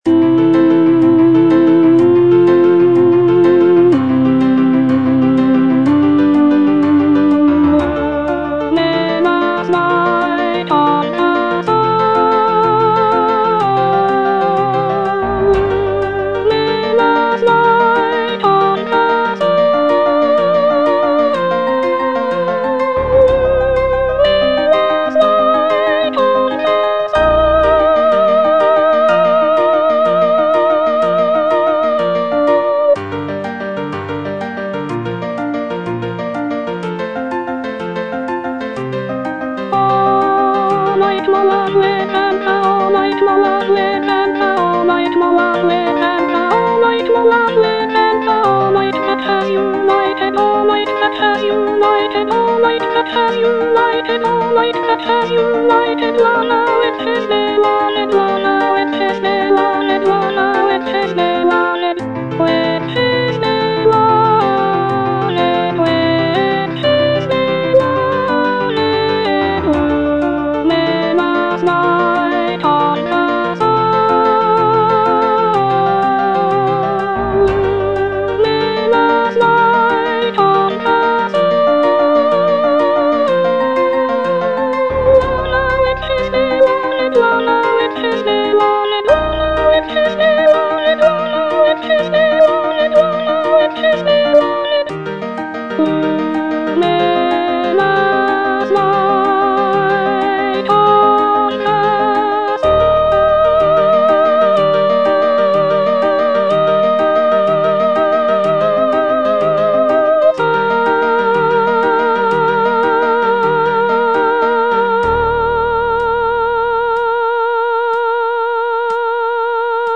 (soprano II) (Voice with metronome) Ads stop